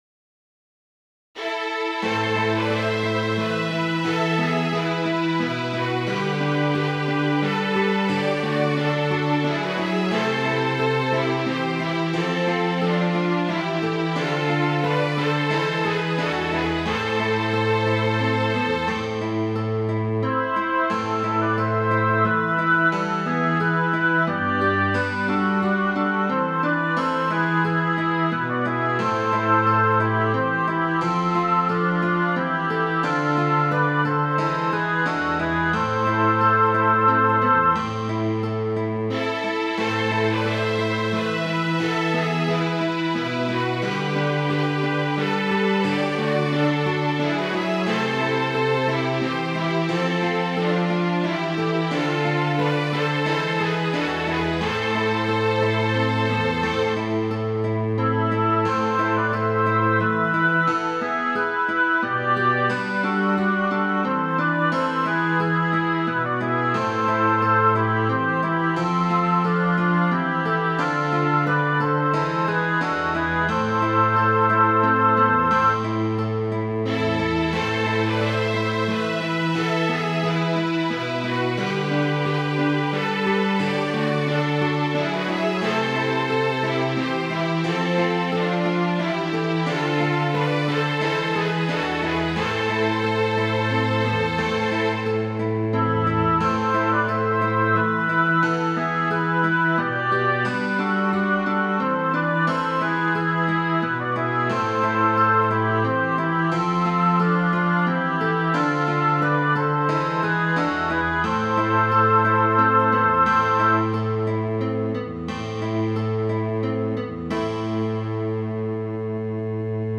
Midi File, Lyrics and Information to The Willow Tree